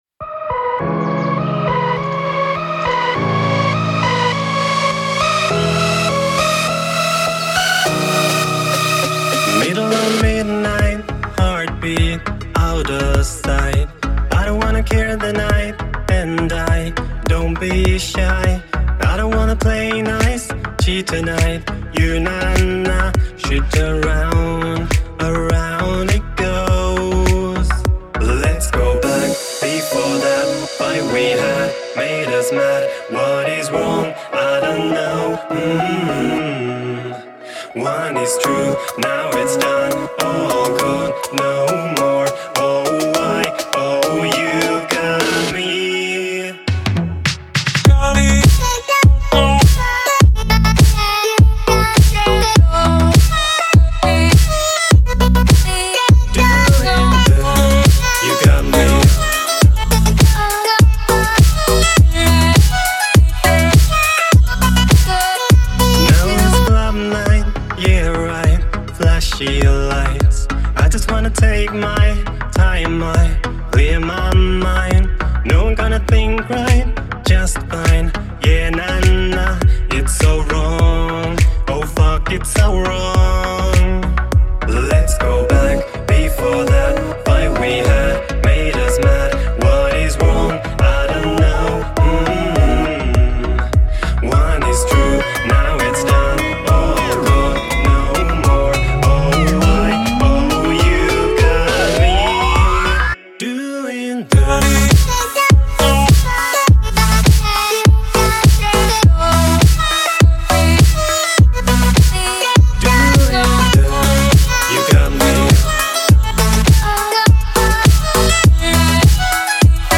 это яркая и зажигательная песня в жанре хип-хоп